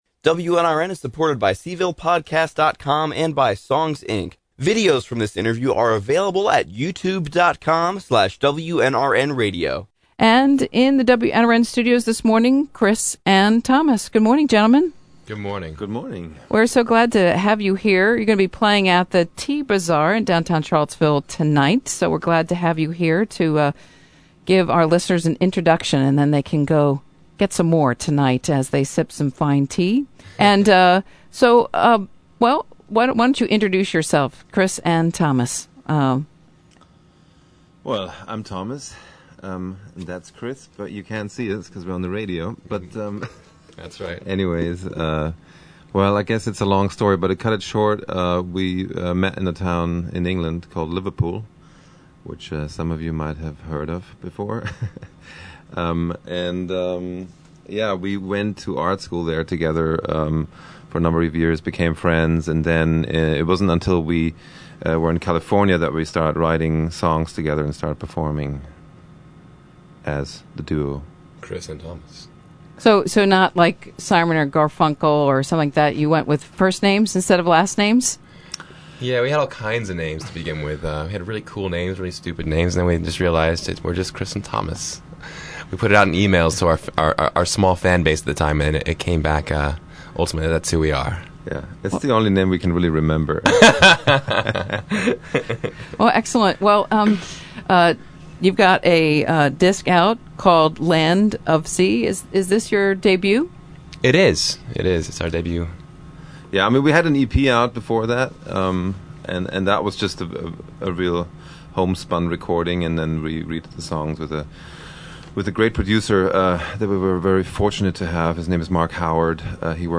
interview
They played a couple tunes for us